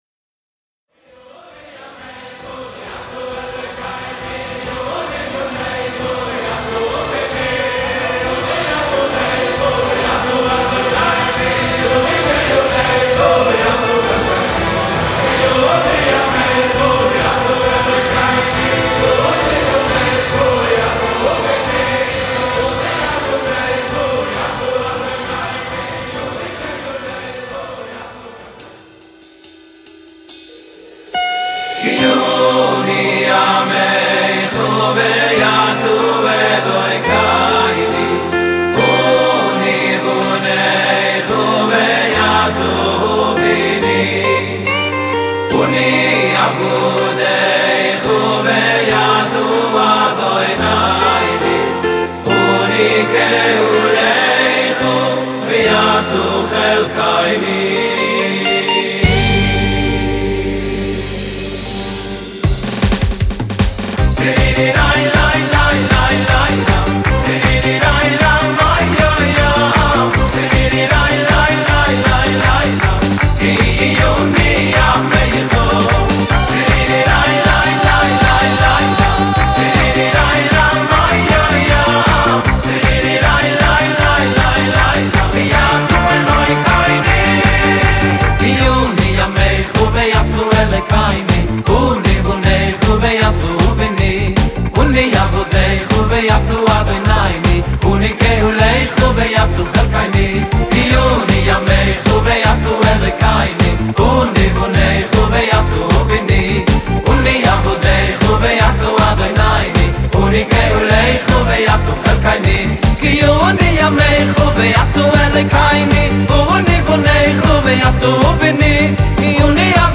המוגשים בצורה אותנטית חתונתית בצירוף טעימות מהחתונה